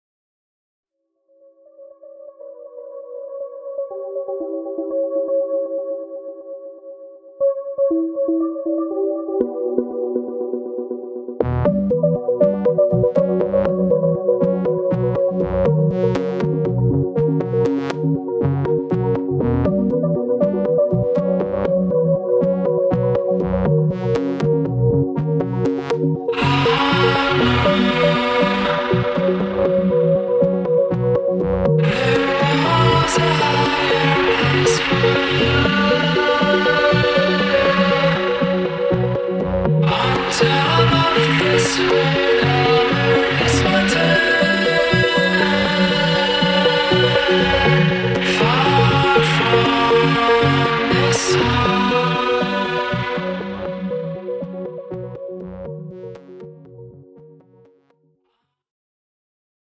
distorted vocal
a cracked mantra